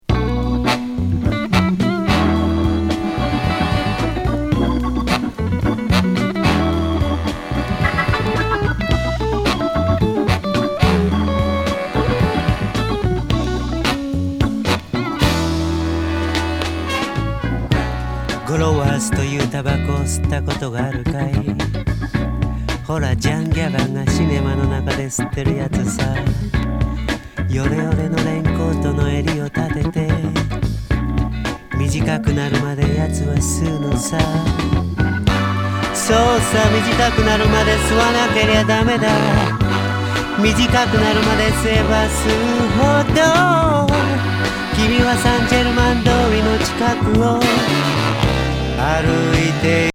モッドなハモンド・オルガン＋ホーンの和グルーヴ・クラシックB面！